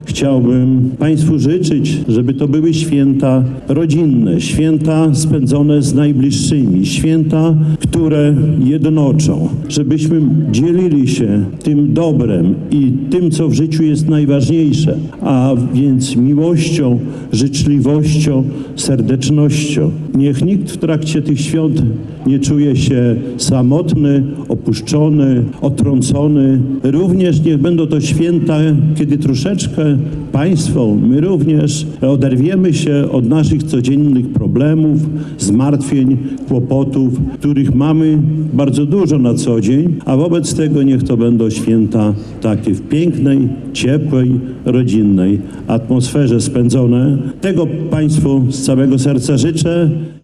W niedzielę (22.12) w hali Ośrodka Sportu i Rekreacji w Suwałkach odbyła się tradycyjna Miejska Wigilia.
Życzenia zebranym składały władze miasta na czele z Czesławem Renkiewiczem, prezydentem Suwałk.